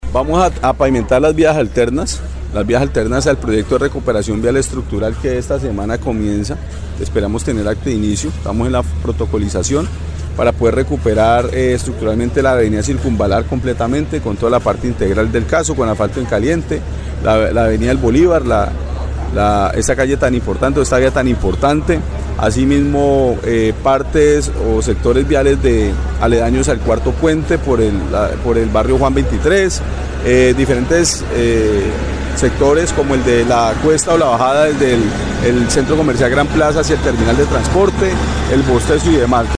Así lo anunció el alcalde Monsalve Ascanio.
ALCALDE_MONSALVE_ASCANIO_VIAS_-_copia_tcUf9wc.mp3